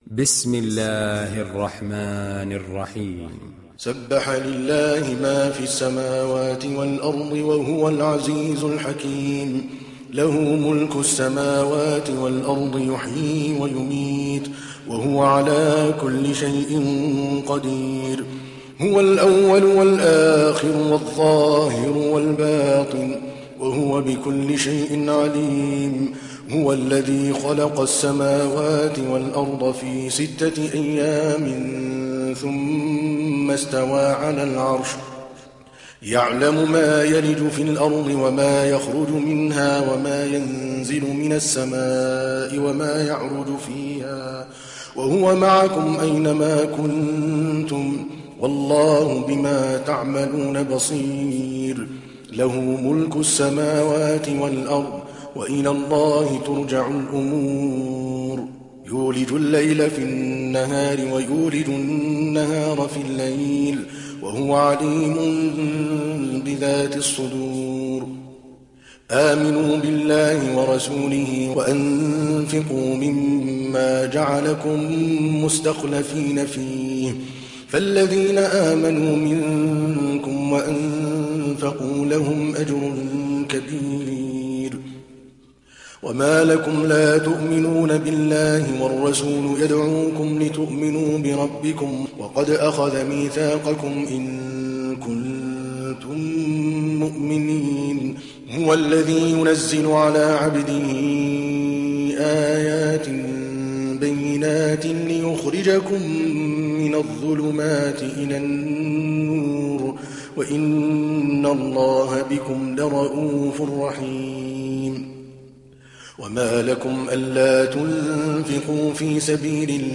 تحميل سورة الحديد mp3 بصوت عادل الكلباني برواية حفص عن عاصم, تحميل استماع القرآن الكريم على الجوال mp3 كاملا بروابط مباشرة وسريعة